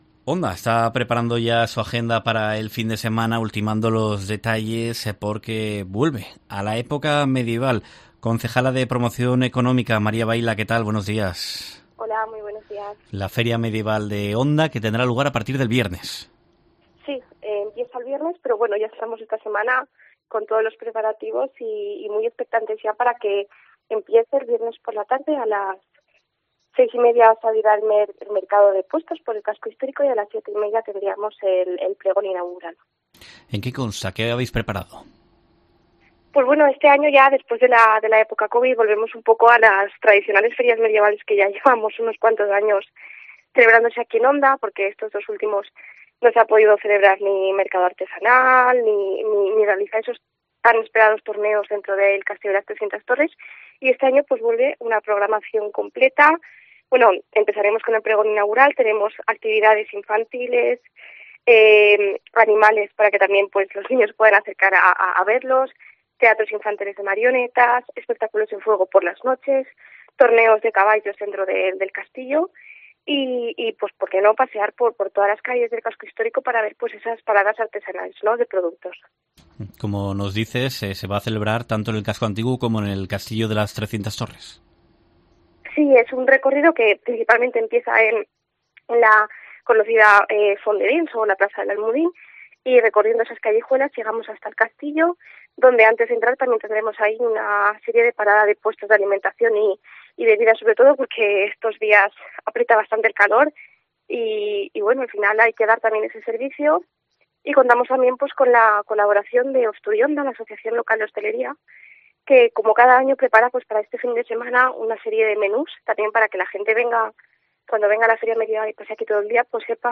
Presenta los actos previstos en COPE, la concejala de Promoción Económica, María Baila